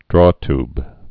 (drôtb, -tyb)